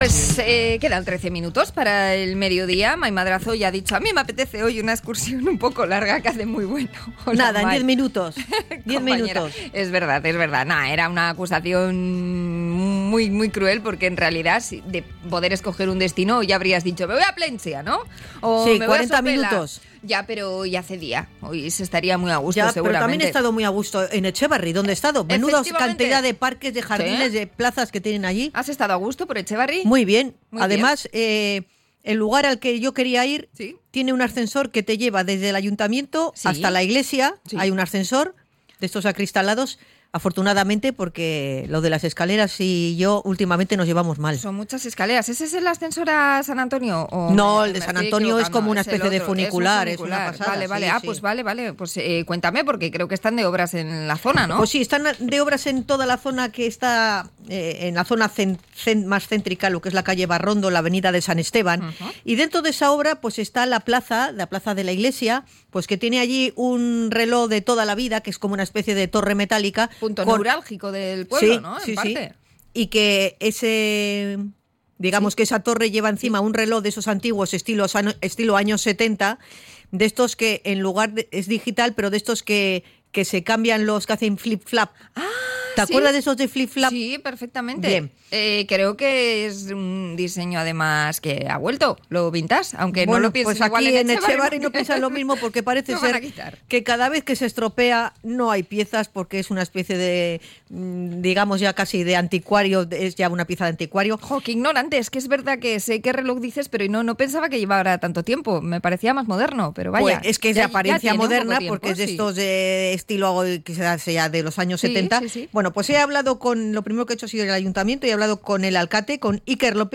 Hablamos con el alcalde de Etxebarri y con vecinos del municipio